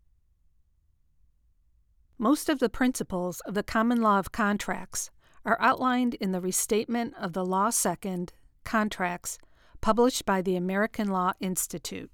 Here is my sample for review. I am interested in what effects are recommended for a chain to improve the recording.